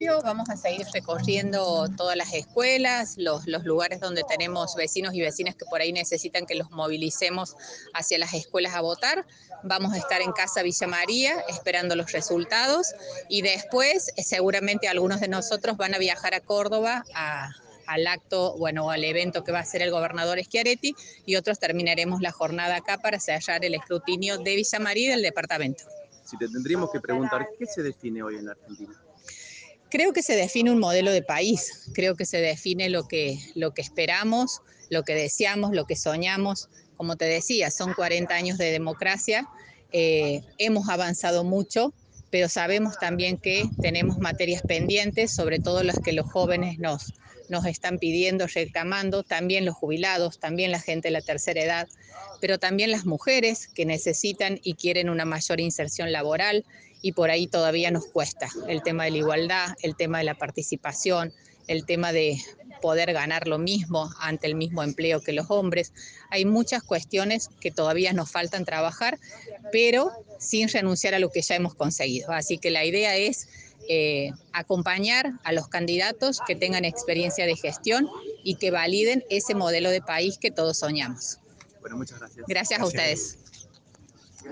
La legisladora electa de la provincia de Córdoba, Verónica Navarro, votó este mediodía en la escuela Justo José de Urquiza, ubicada en barrio Mariano Moreno.